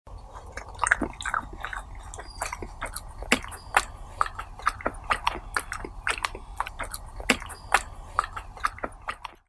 Кабарга что-то пережевывает